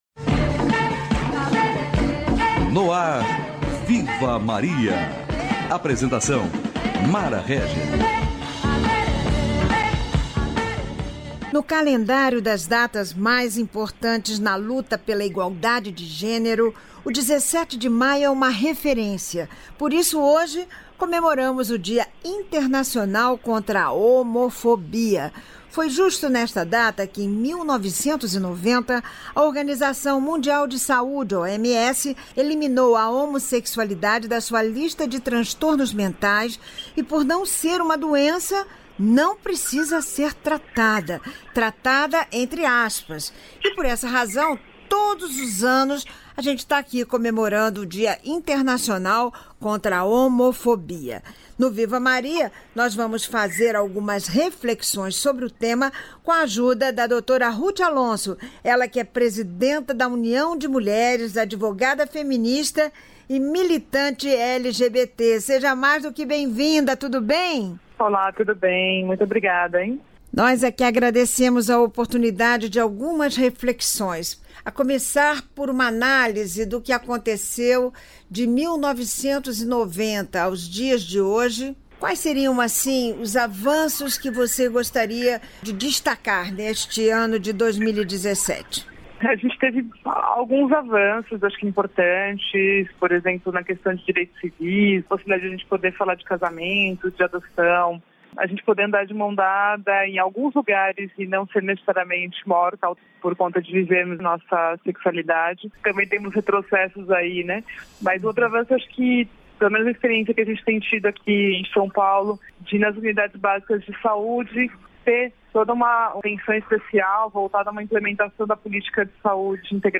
Advogada e militante LGBT faz balanço de avanços e desafios no combate ao preconceito